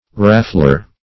raffler - definition of raffler - synonyms, pronunciation, spelling from Free Dictionary Search Result for " raffler" : The Collaborative International Dictionary of English v.0.48: Raffler \Raf"fler\ (r[a^]f"fl[~e]r), n. One who raffles.